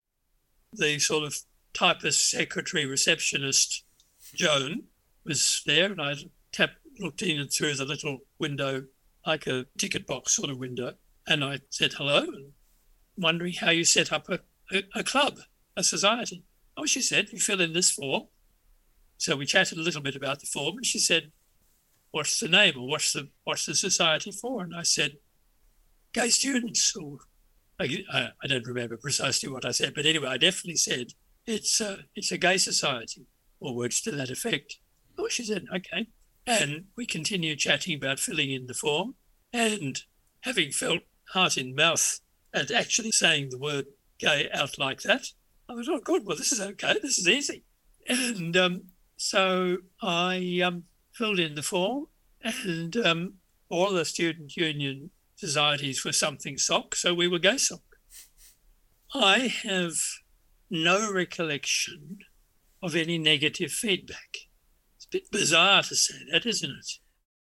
Excerpt from an interviewed for the Generation UCL project